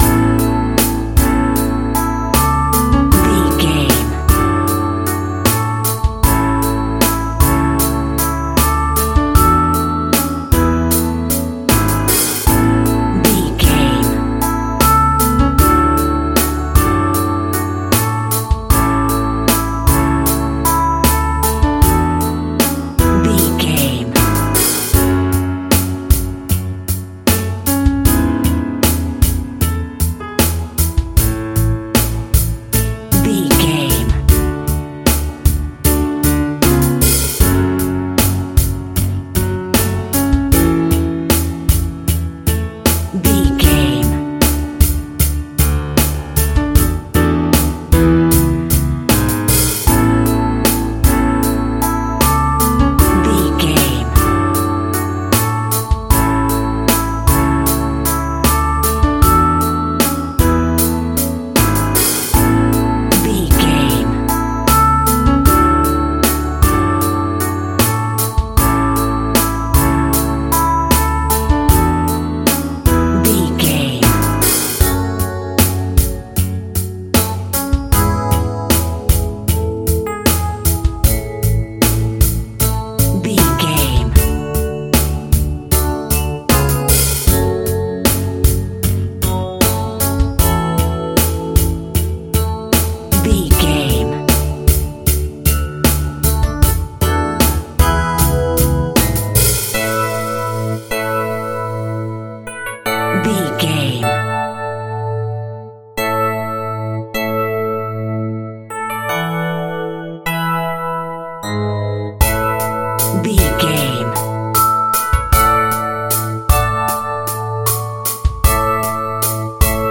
Ionian/Major
pop rock
Bubblegum pop
Teen pop
dance pop
pop instrumentals
light
drums
bass
keyboards
guitars